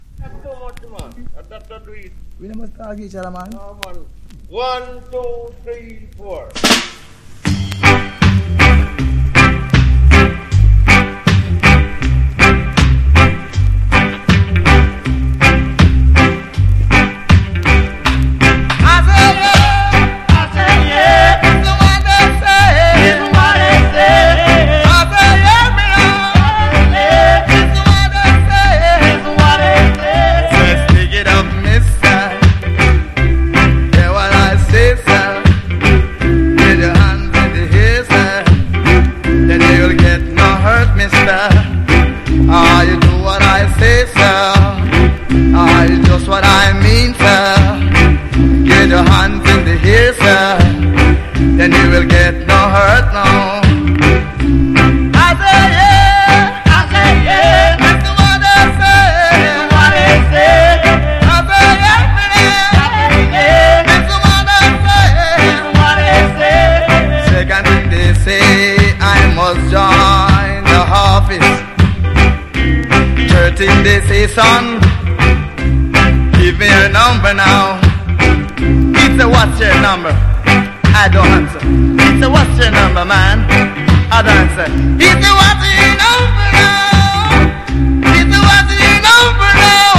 • REGGAE-SKA
SKA / ROCK STEADY